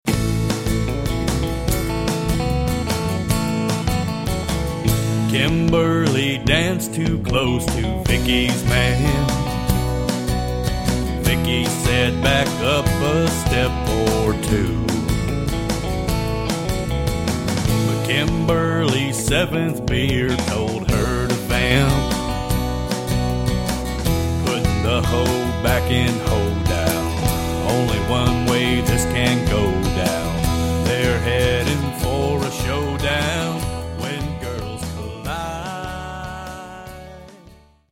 Sample from the Vocal MP3